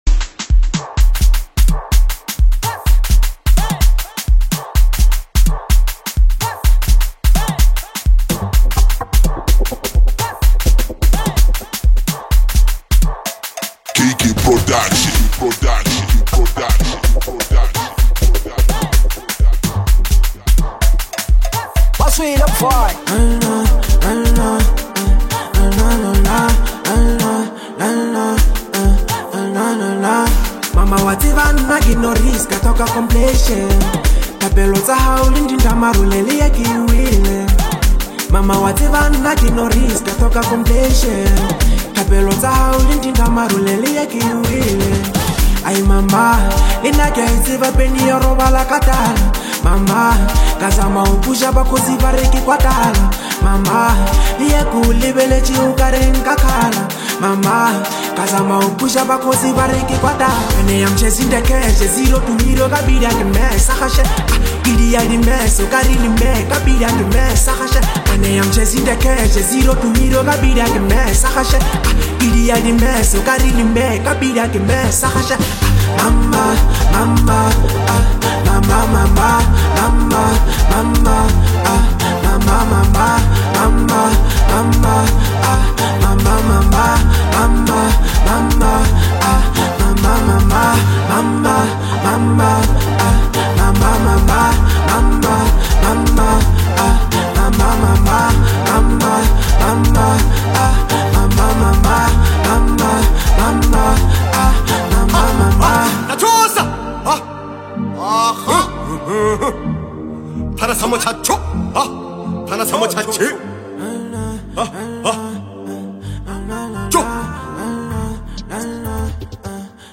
warm,emotional tribute